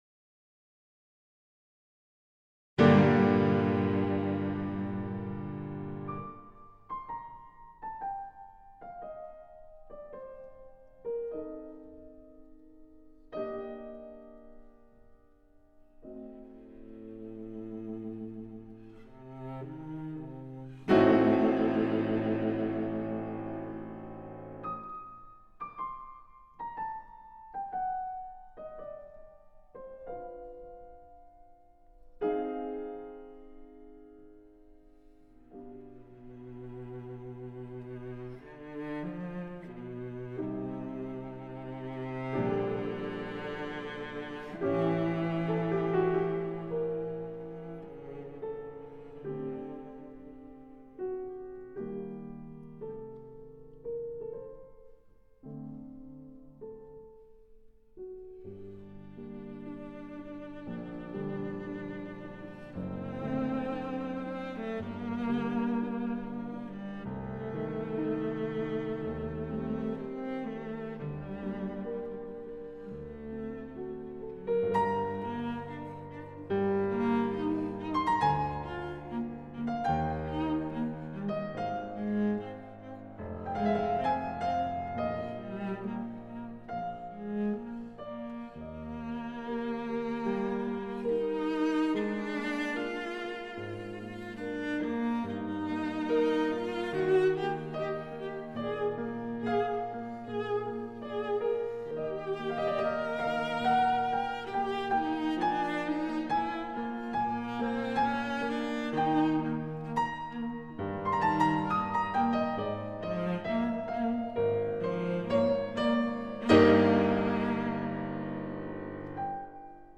Beethoven, Sonata for Piano and Cello in G Minor Op. 5, No. 2; 1. Adagio sostenuto
01-sonata-for-piano-and-cello-in-g-minor-op-5-no-2-1-adagio-sostenuto-e-esp.mp3